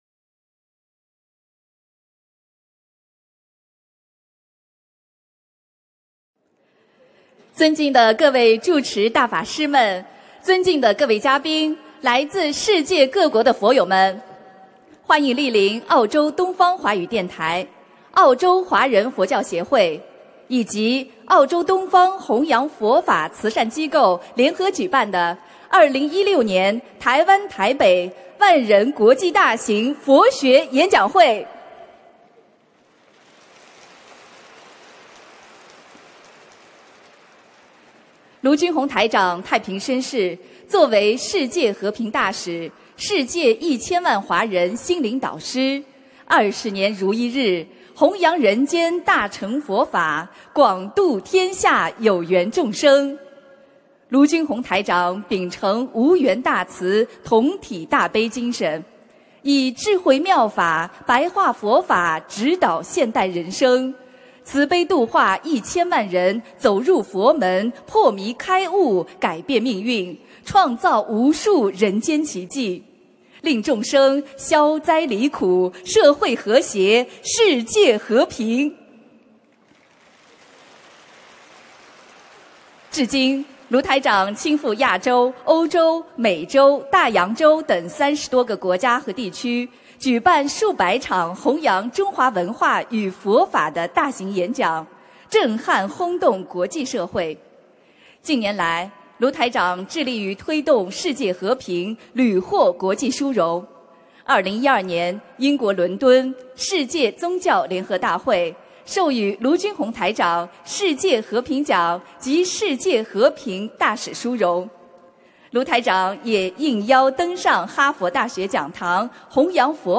2016年10月2日中国台湾台北小巨蛋万人解答会开示（视音文图） - 2016年 - 心如菩提 - Powered by Discuz!